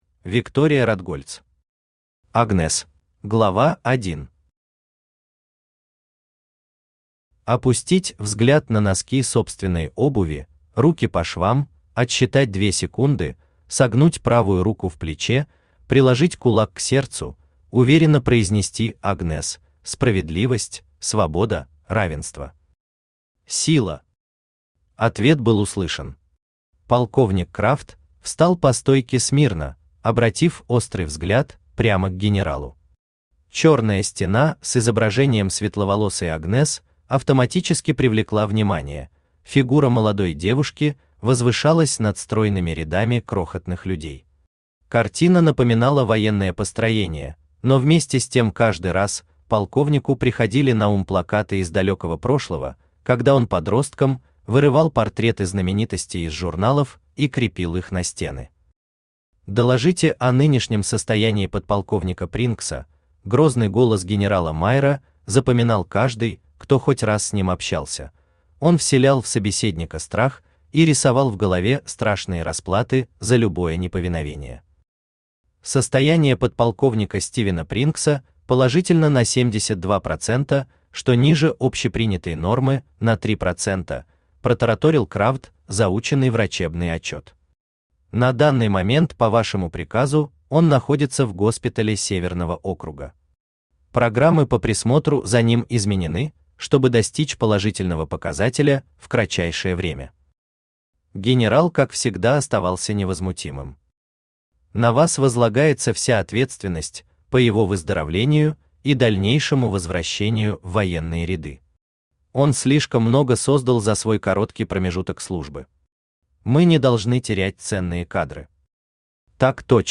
Аудиокнига Агнесс | Библиотека аудиокниг
Aудиокнига Агнесс Автор Виктория Ратгольц Читает аудиокнигу Авточтец ЛитРес.